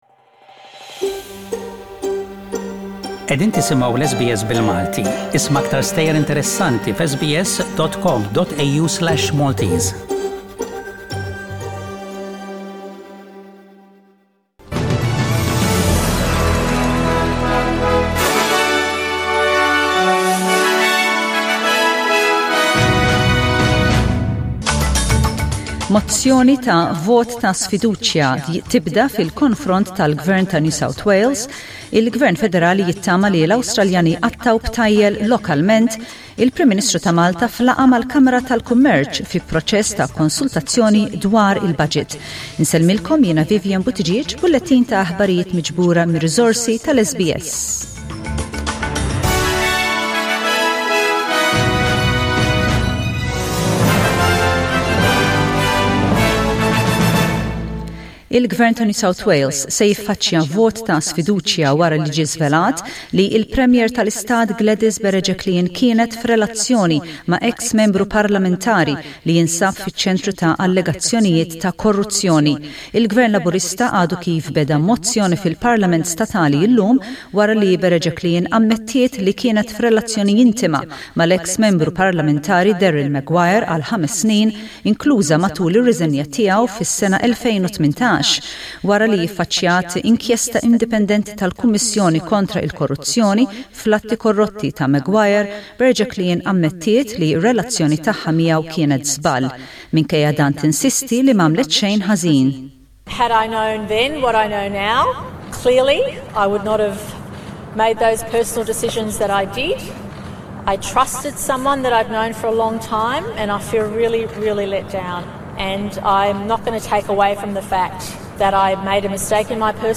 SBS Radio | News in Maltese: 13/10/20